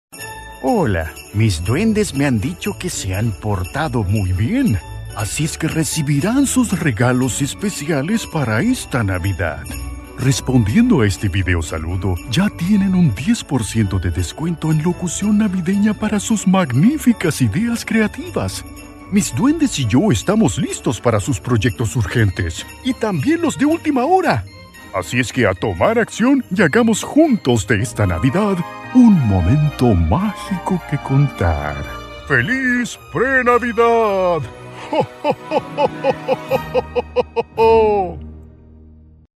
Español (América Latina)
Comercial, Natural, Travieso, Versátil, Empresarial